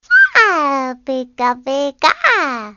Звуки Пикачу